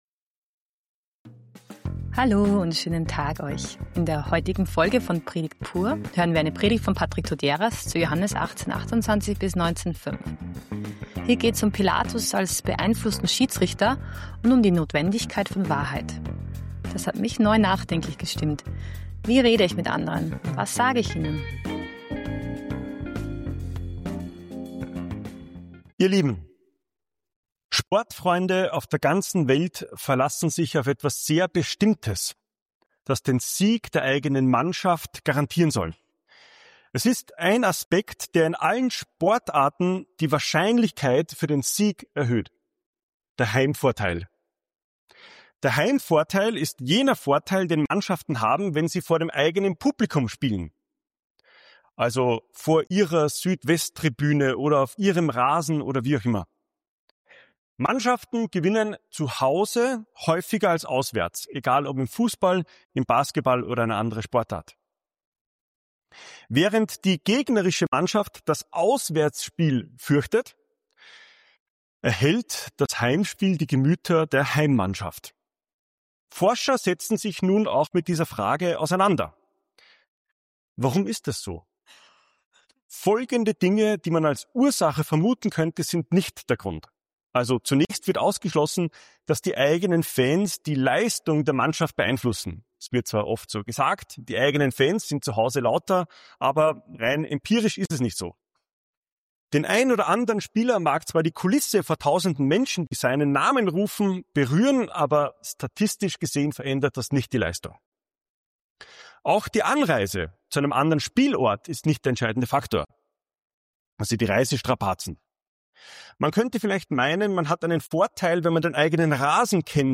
Pilatus als Schiedsrichter? In der Predigt wird gezeigt, wie der Heimvorteil im Sport und der Druck der Menge im Prozess Jesu zusammenhängen.